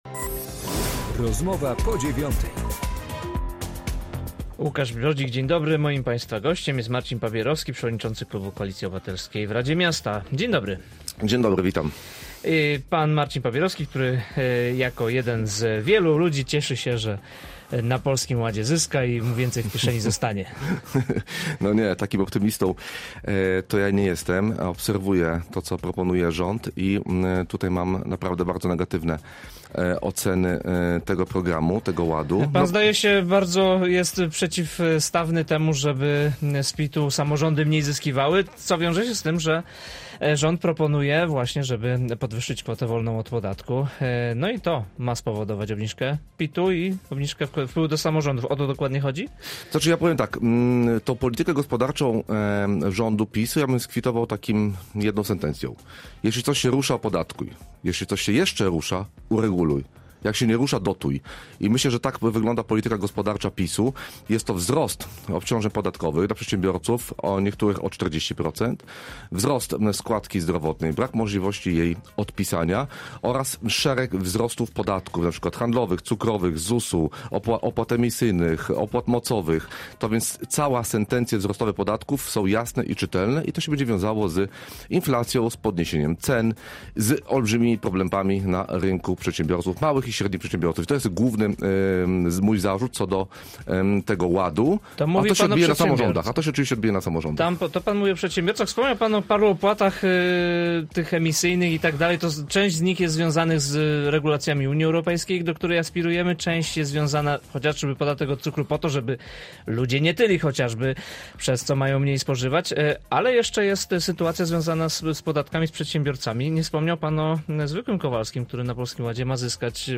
Z przewodniczącym klubu radnych Koalicji Obywatelskiej rozmawia